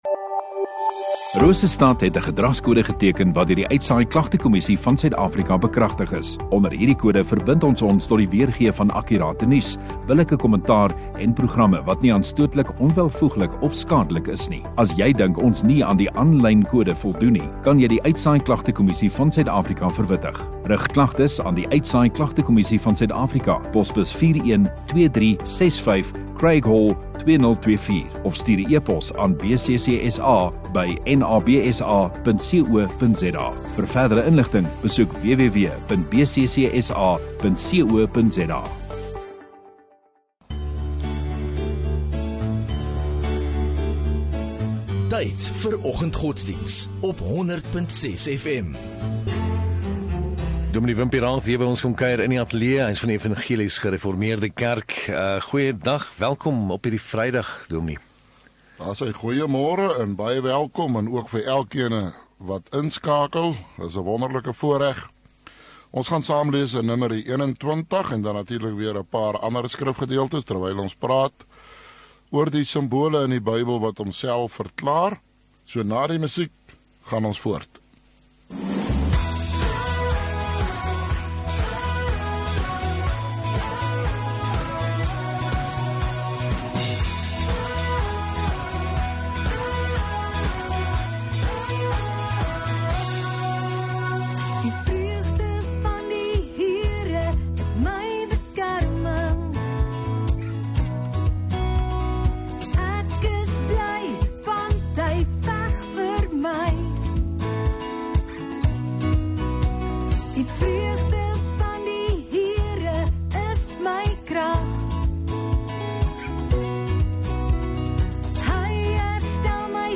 20 Oct Vrydag Oggenddiens